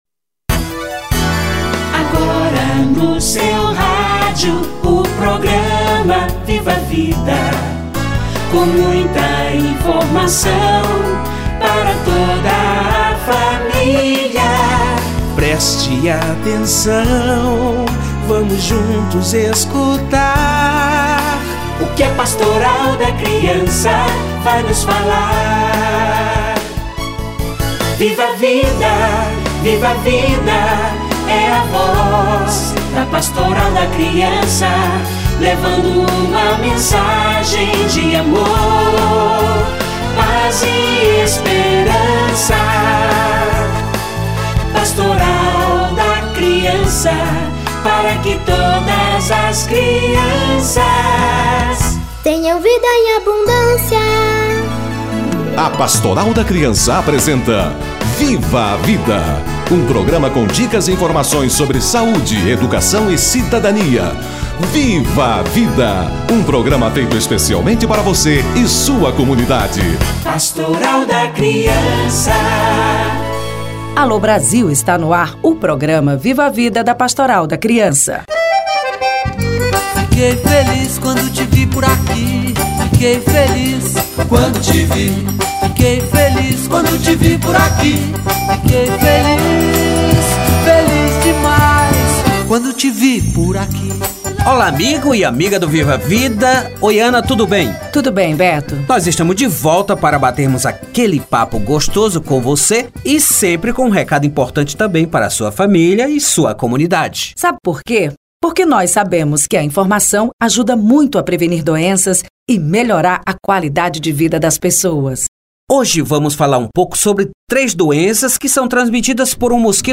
Dengue e Chikungunya - Entrevista